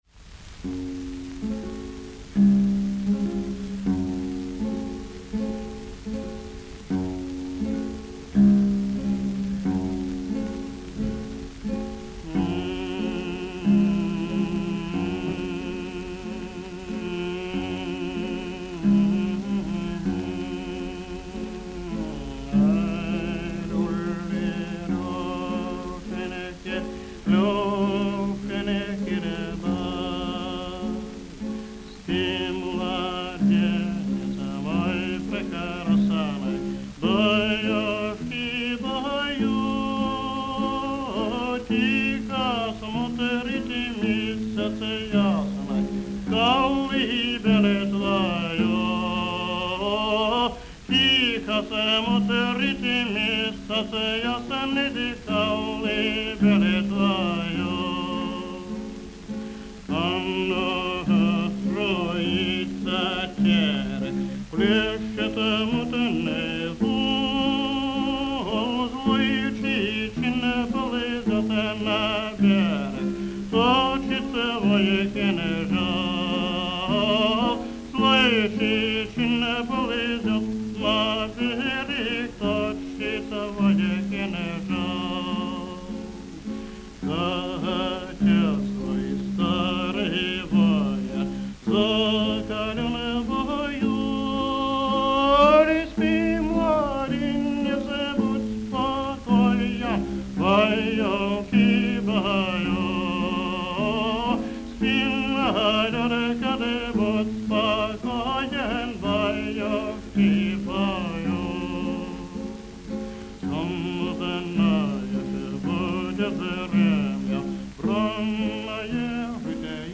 Казачья Колыбельная